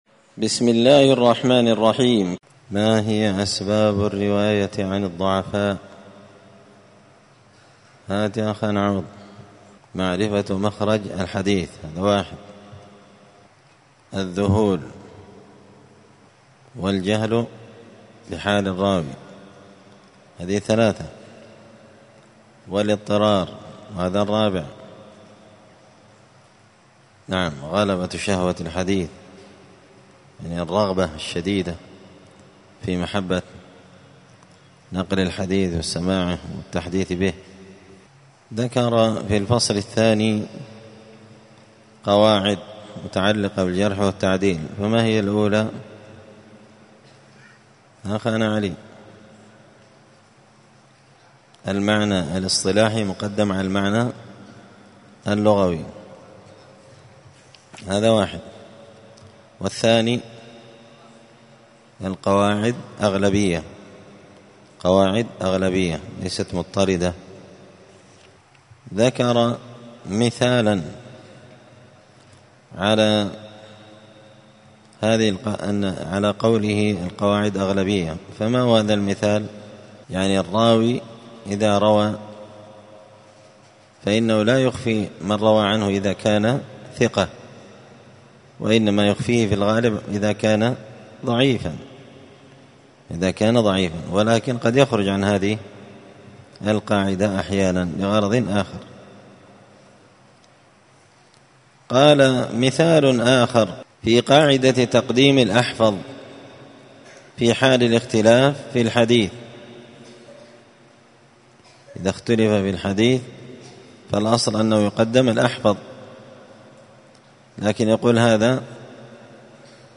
*الدرس الرابع عشر (14) تابع لفصل من أسباب الرواية عن الضعفاء*
مسجد الفرقان قشن_المهرة_اليمن